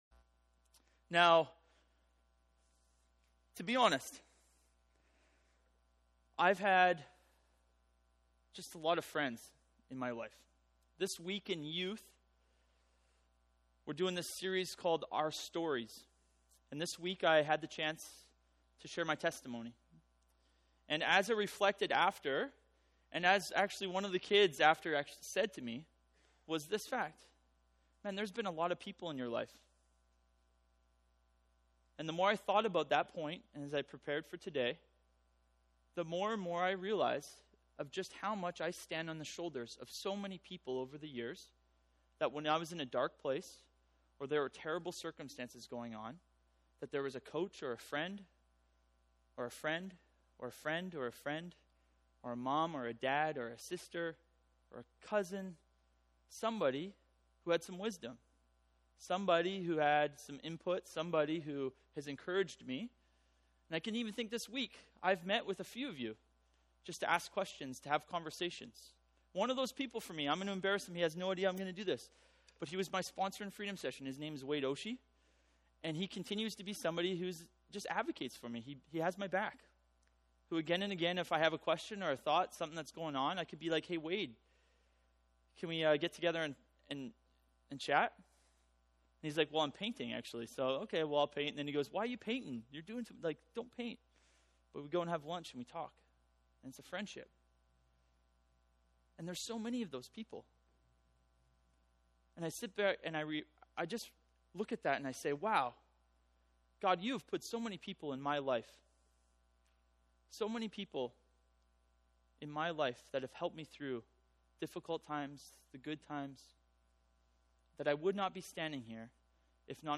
Multiple passages from Proverbs Sermon